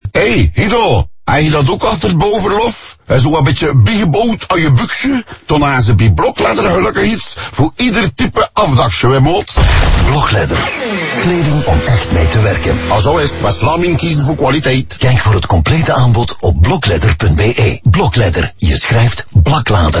Telkens worden scenario’s uitgewerkt waarin bouwvakkers in dialect met elkaar communiceren, van West-Vlaams tot Kempisch en Limburgs.
• West-Vlaams
West-Vlaams.mp3